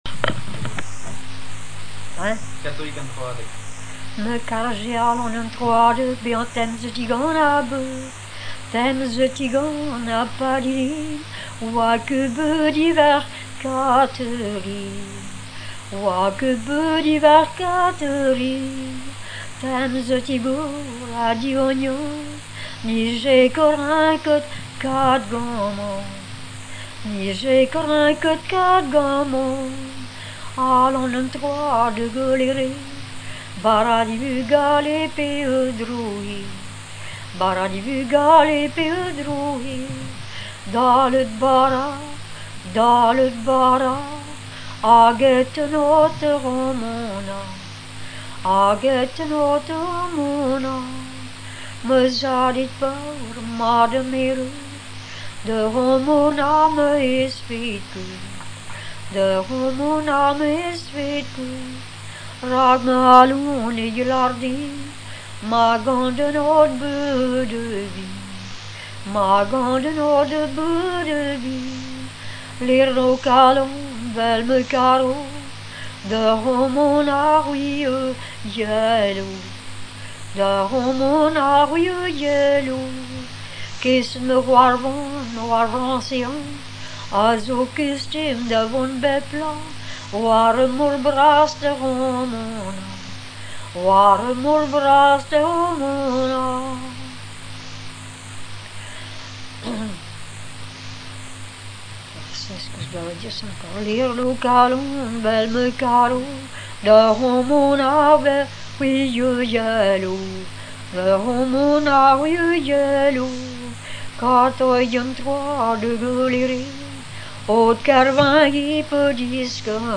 - Gwerz (complainte en breton) « katrig an Troadeg »